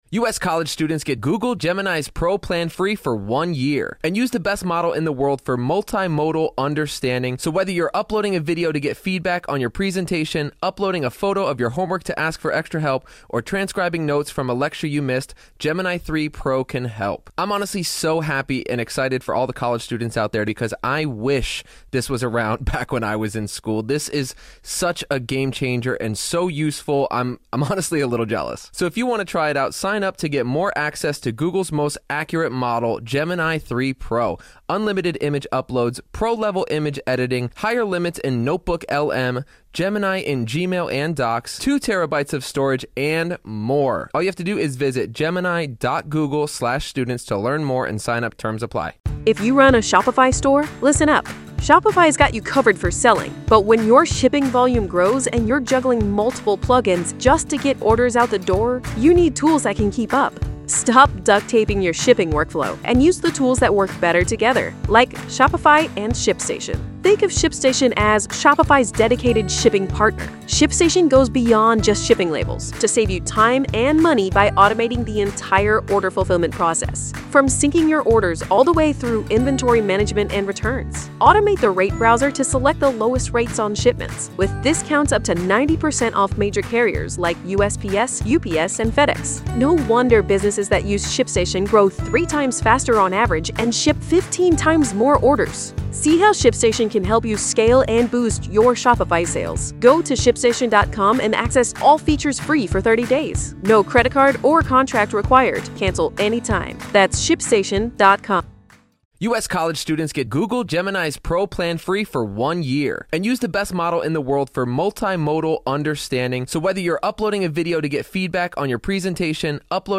Every episode beams you directly into the heart of the courtroom, with raw, unedited audio from testimonies, cross-examinations, and the ripple of murmurs from the gallery.
We accompany the live audio with expert legal insights, breaking down the day's events, the strategies in play, and the potential implications of each revelation.